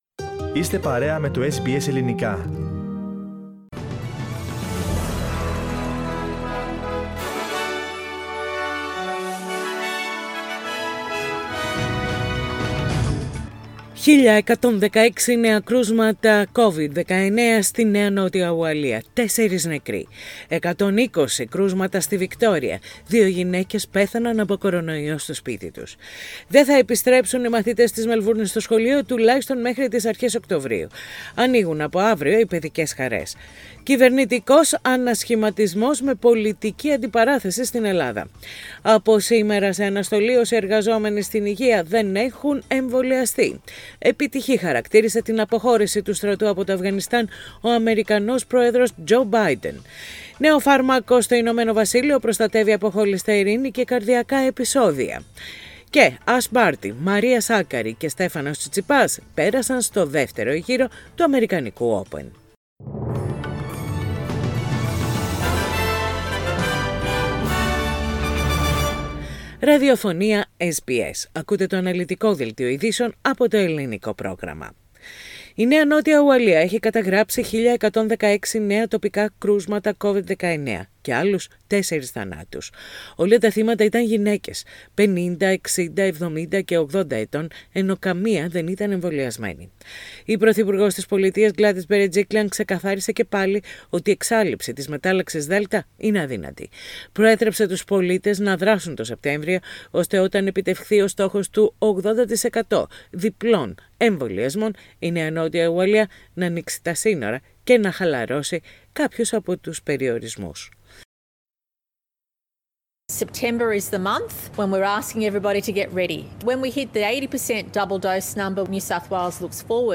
Ειδήσεις στα Ελληνικά - Τετάρτη 1.9.21